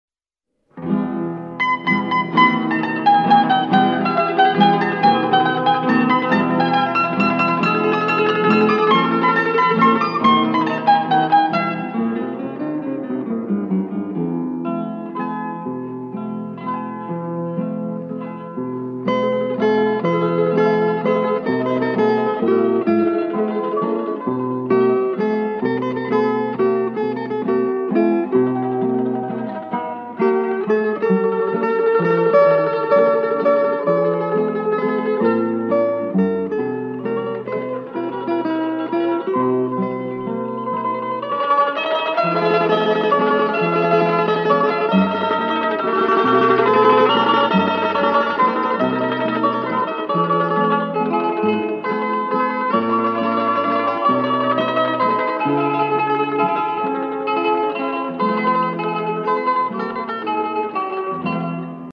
• Теги: минусовка